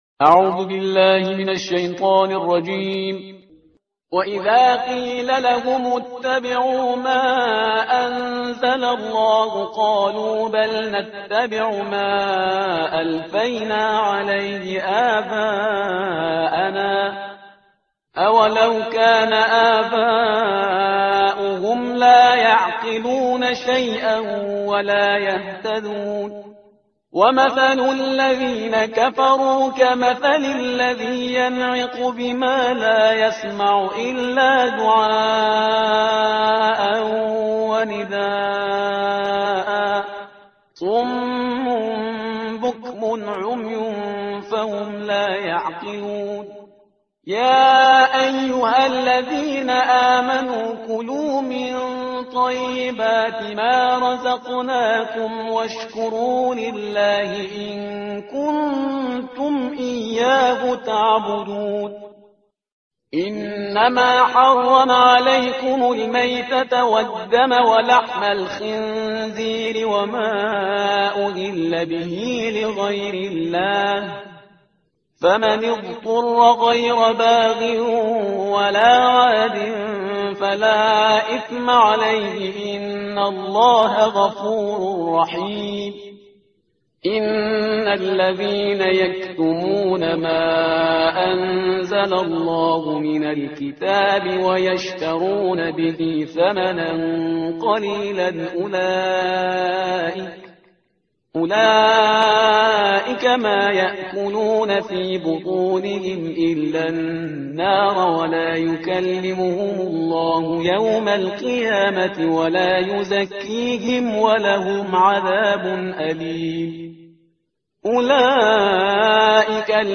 ترتیل صفحه 26 قرآن – جزء دوم سوره بقره